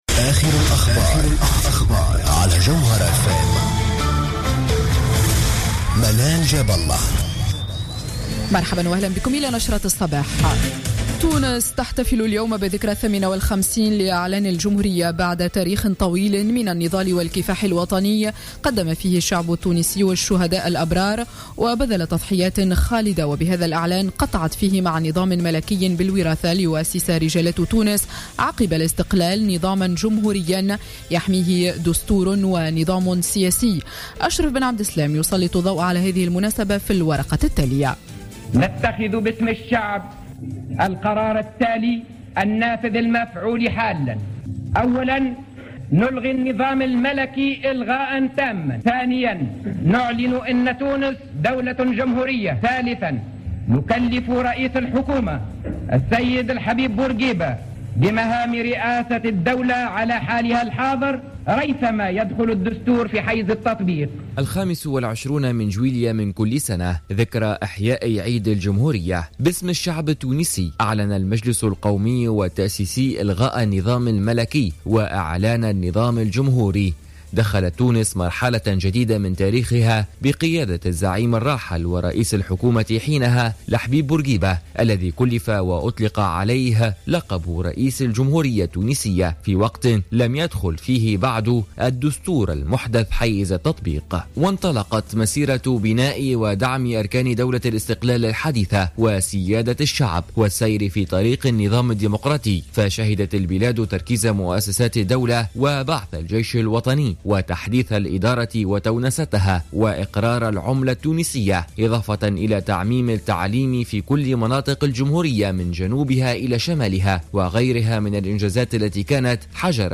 نشرة أخبار السابعة صباحا ليوم السبت 25 جويلية 2015